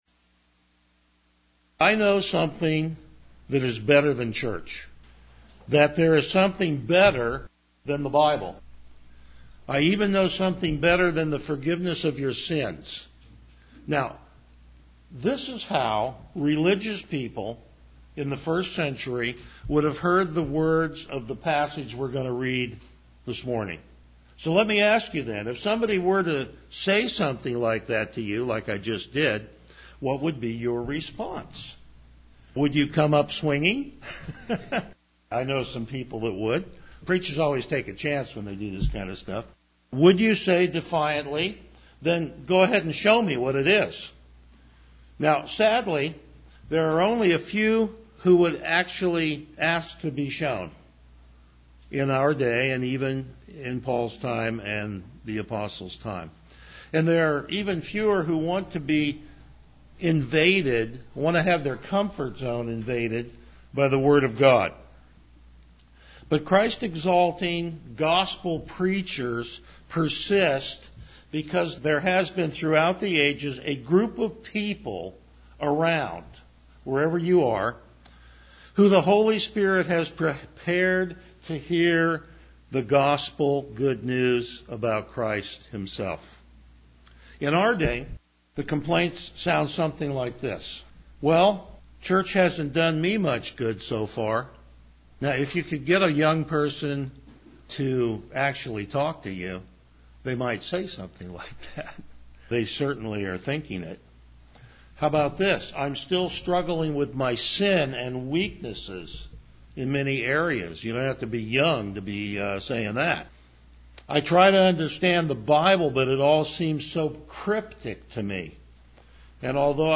Passage: Hebrews 7:1-10 Service Type: Morning Worship
Verse By Verse Exposition